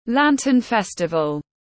Tết Nguyên Tiêu tiếng anh gọi là Lantern festival, phiên âm tiếng anh đọc là /ˈlæntənˈfɛstəvəl/
Lantern-Festival-.mp3